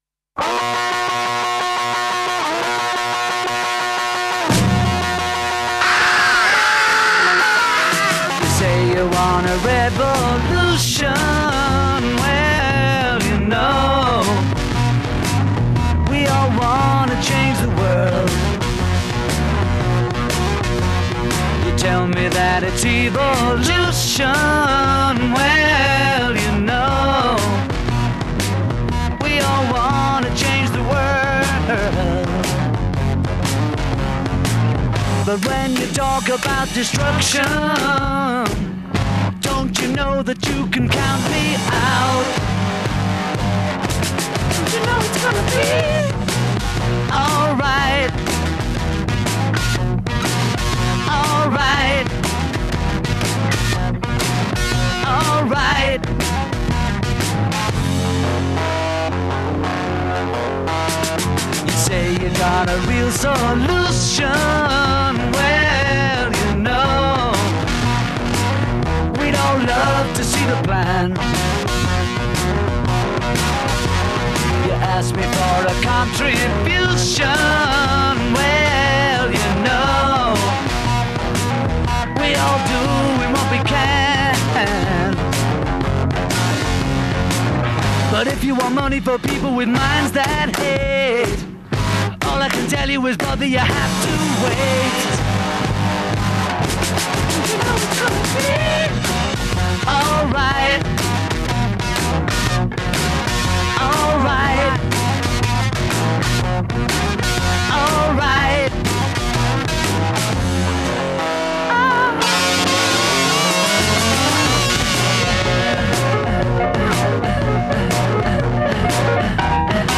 Intro 8 Distorted guitar introduction
Verse 13+13+11 Solo, double-tracked vocal. a